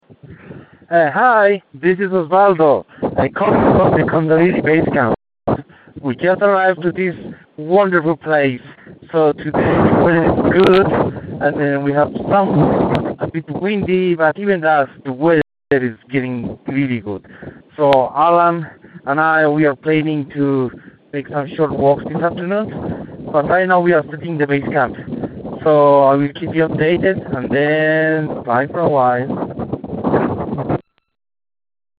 Bolivia Everest Prep Climb Dispatch